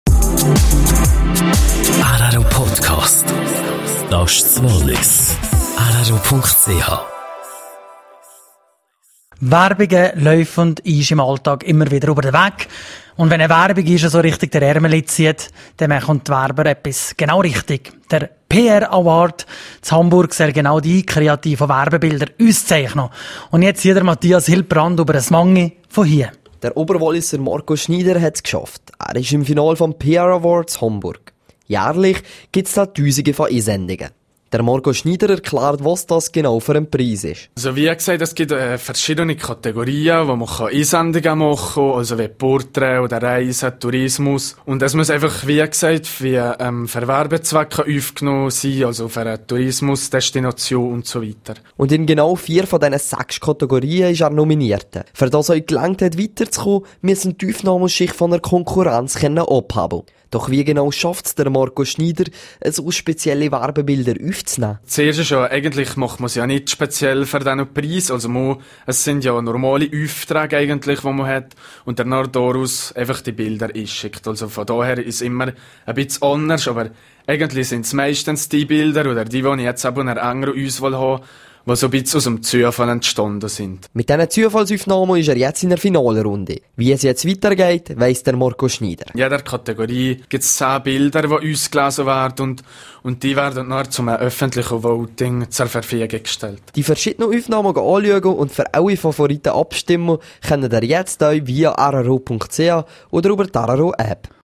PR-Award: Interview